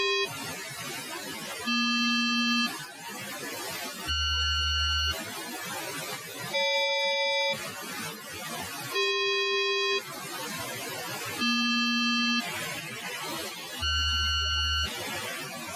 UHF_Milsat_Multitone_DSB.mp3